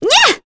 Selene-Vox_Attack8.wav